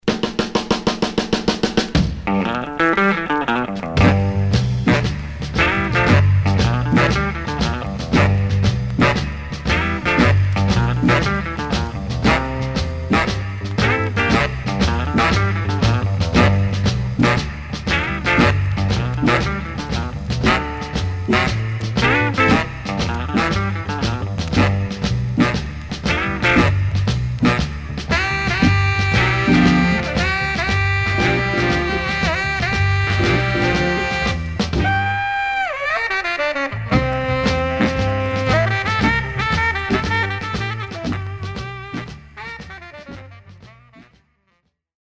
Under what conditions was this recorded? Stereo/mono Mono Some surface noise/wear